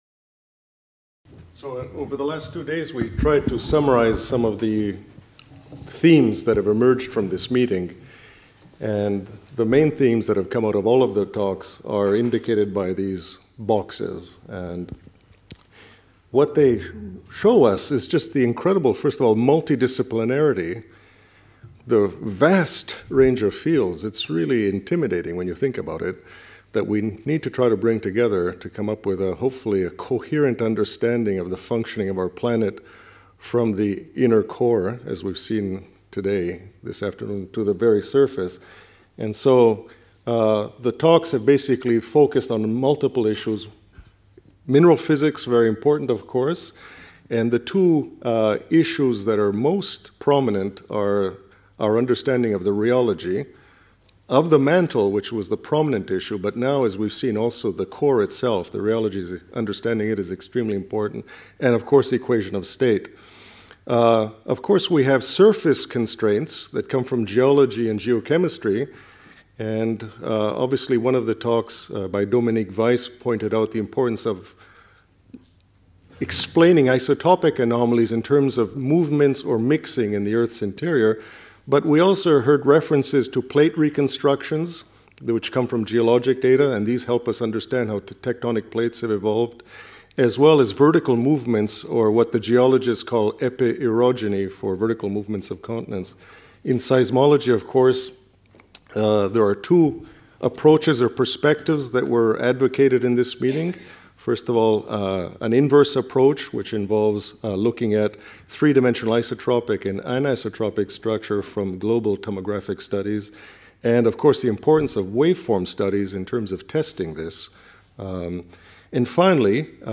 Panel Discussion | Collège de France